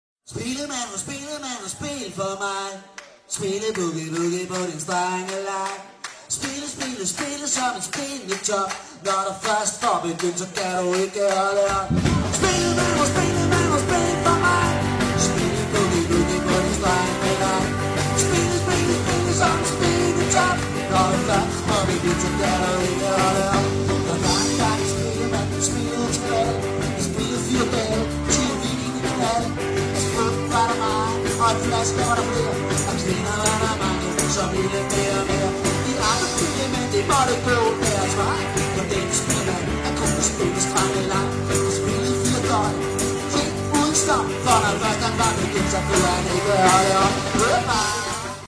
• Coverband